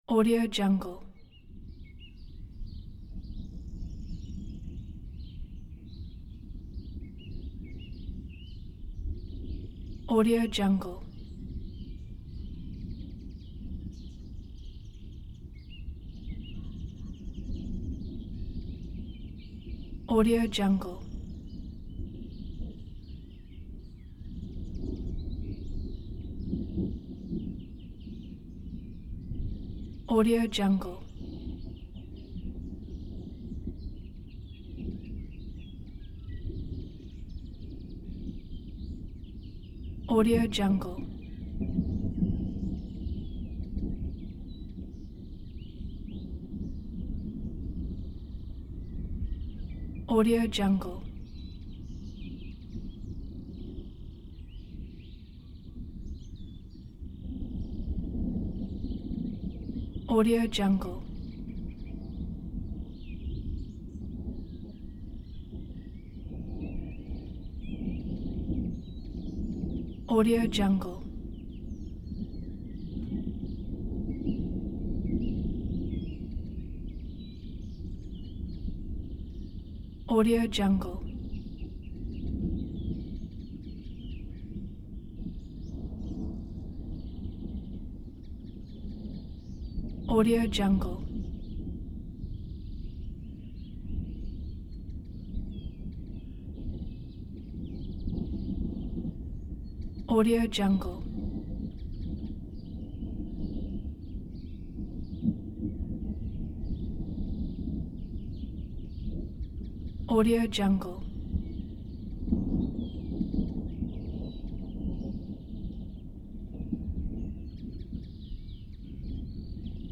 دانلود افکت صدای نزدیک شدن طوفان با صدای پرندگان
از صدای وزش باد شدید و غرش ابرها گرفته تا صدای پرندگان وحشت‌زده، همه چیز در این فایل موجود است.
• کیفیت استودیویی: صدای ضبط شده در محیط طبیعی و با استفاده از تجهیزات حرفه‌ای، کیفیت بسیار بالایی دارد و به پروژه‌های شما حرفه‌ای‌تر می‌بخشد.
• ترکیب صداهای متنوع: این فایل ترکیبی از صداهای مختلف مانند وزش باد، غرش ابرها و صدای پرندگان وحشت‌زده است که به شما امکان می‌دهد تا اتمسفر کاملی از یک طوفان در حال نزدیک شدن را ایجاد کنید.
16-Bit Stereo, 44.1 kHz